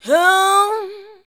UUUH 4.wav